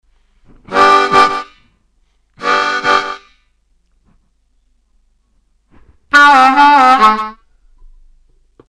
Funkin’ it up on the blues harmonica